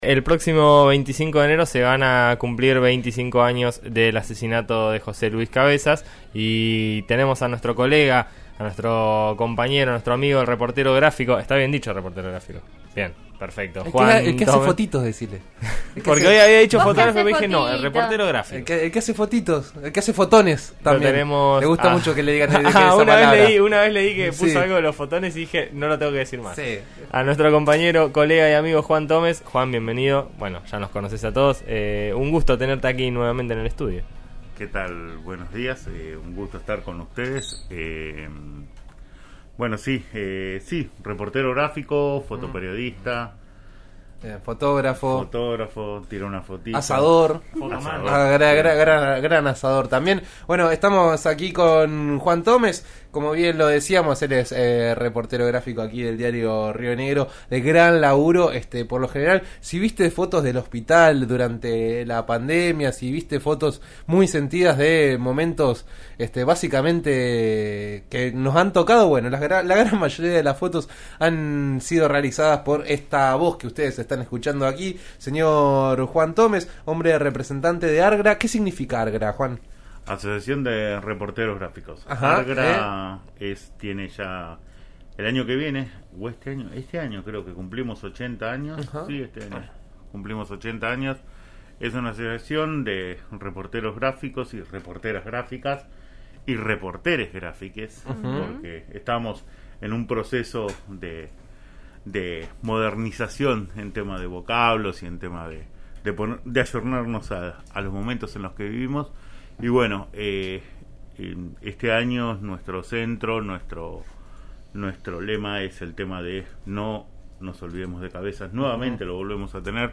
En eso estamos de RN Radio (89.3) dialogó